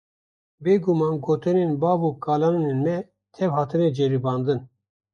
Pronounced as (IPA) /bɑːv/